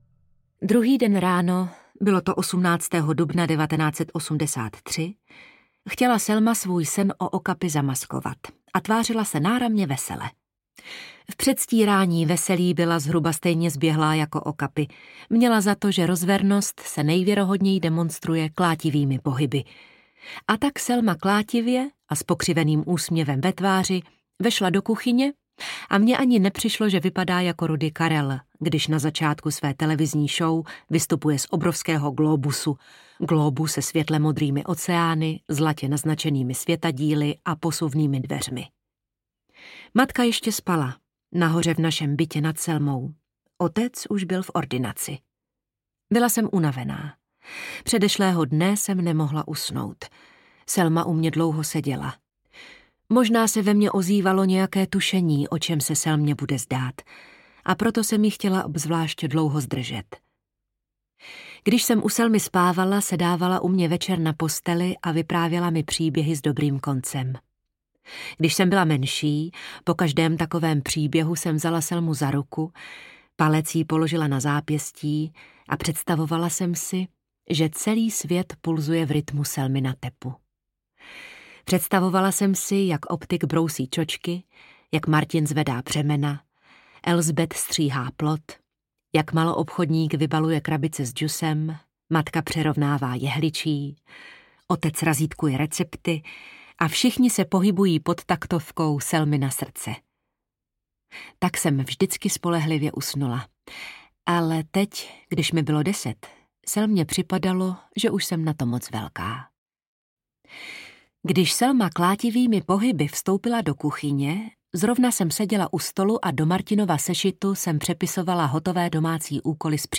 Ukázka z knihy
Čte Helena Dvořáková.
Vyrobilo studio Soundguru.